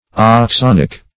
Search Result for " oxonic" : The Collaborative International Dictionary of English v.0.48: Oxonic \Ox*on"ic\, a. [Prob. glyoxalic + carbonic.]